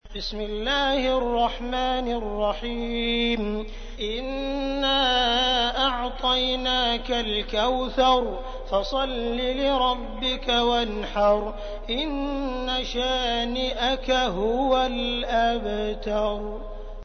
Audio icon ترتیل سوره کوثر با صدای عبد الرحمن سديس ازعربستان (68.13 KB)